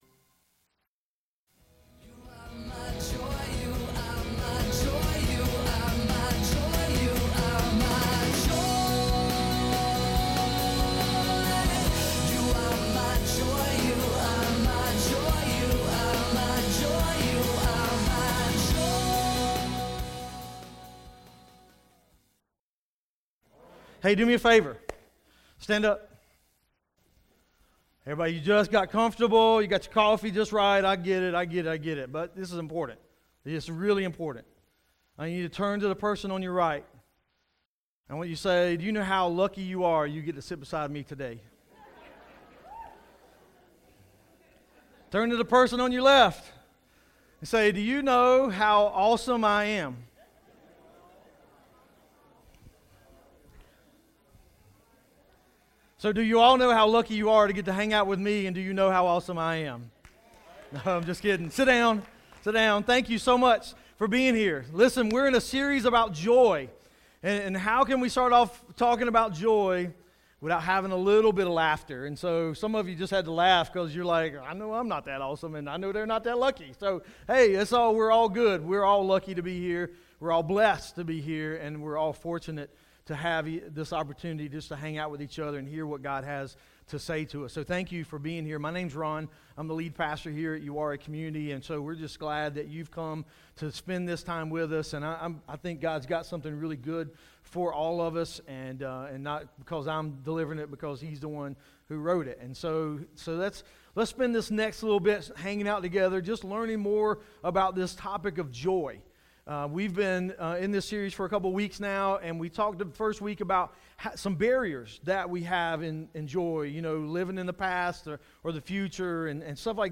Joy – Week 3 This week’s message helps us to not get lost in the things around us, hoping to find joy in them.